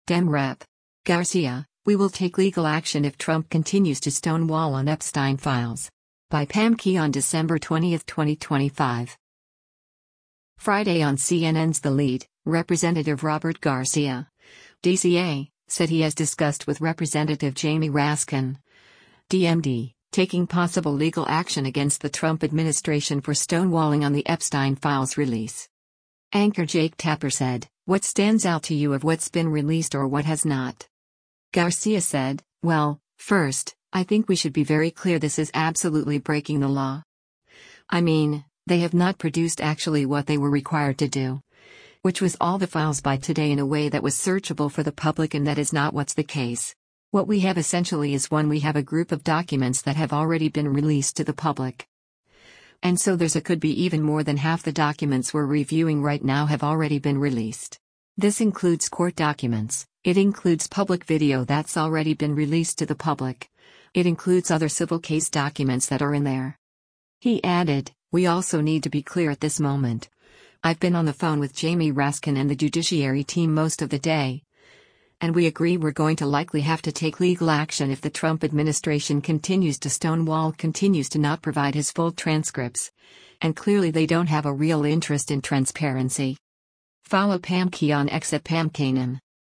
Friday on CNN’s “The Lead,” Rep. Robert Garcia (D-CA) said he has discussed with Rep. Jamie Raskin (D-MD) taking possible legal action against the Trump administration for stonewalling on the Epstein files release.
Anchor Jake Tapper said, “What stands out to you of what’s been released or what has not?”